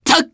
Fluss-Vox_Jump_jp.wav